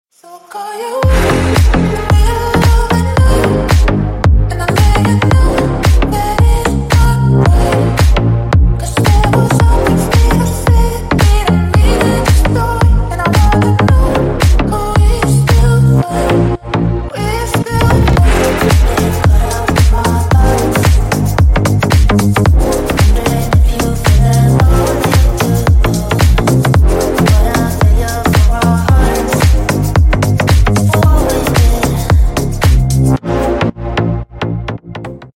Клубные Рингтоны » # Громкие Рингтоны С Басами
Танцевальные Рингтоны
Скачать припев песни